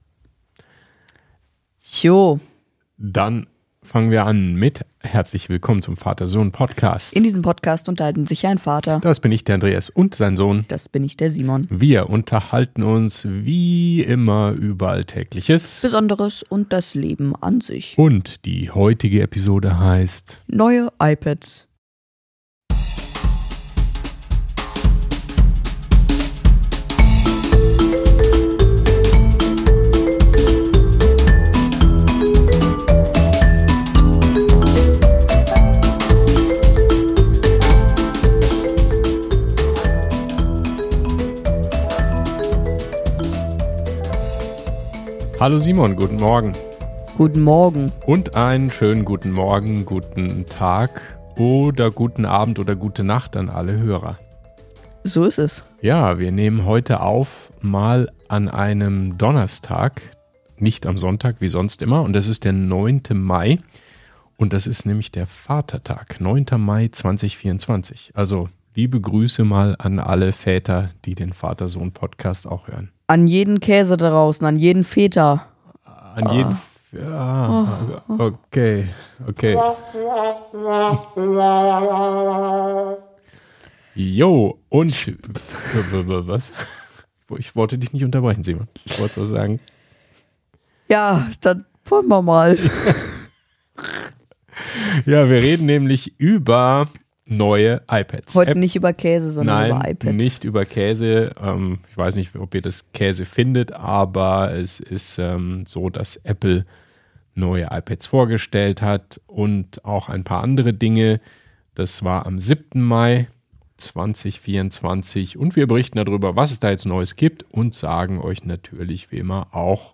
Vater und Sohn. Reden.